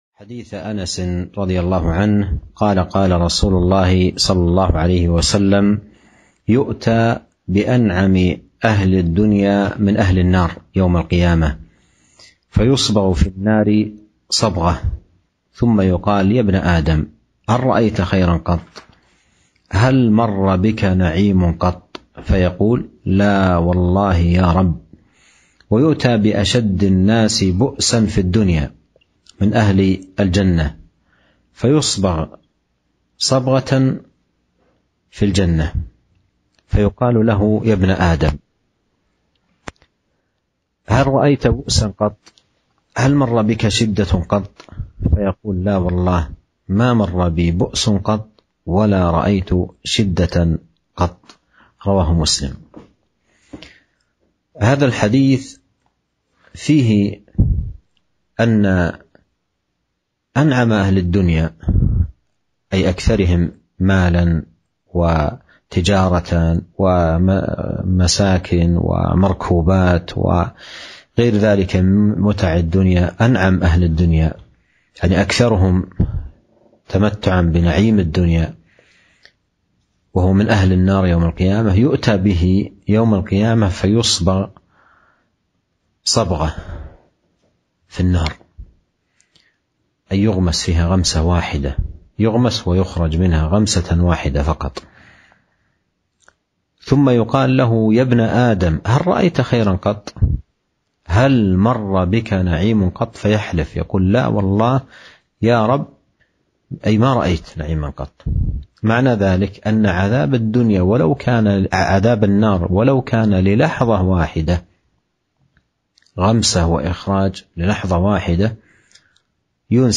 462 – شرح حديث يؤتي بأنعم أهل الدنيا من أهل النار يوم القيامة